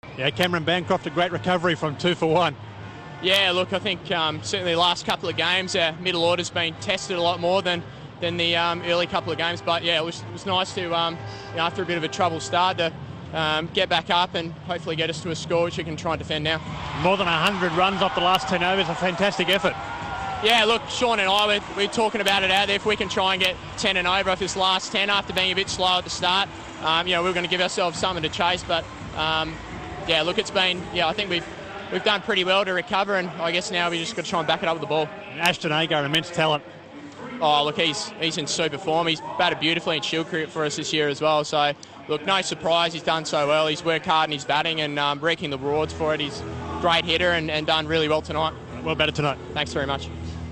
INTERVIEW: Cameron Bancroft speaks after his innings of 72 against Thunder